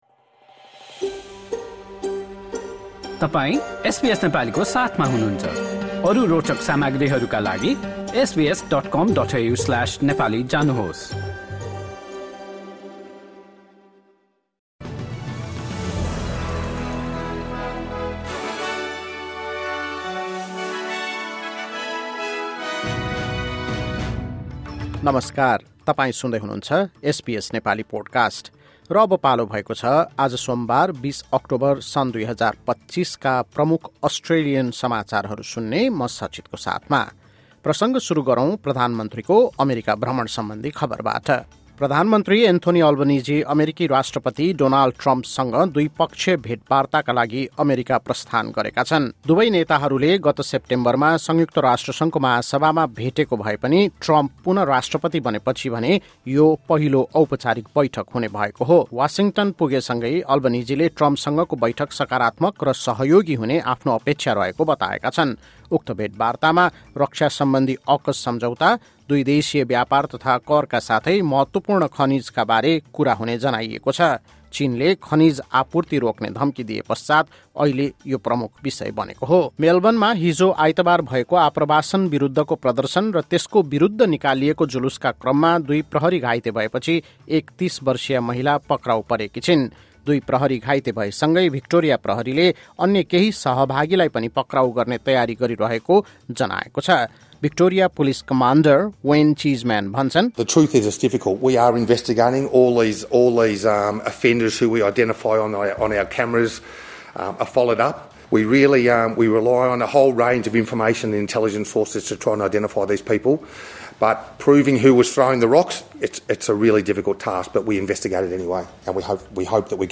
SBS Nepali Australian News Headlines: Monday, 20 October 2025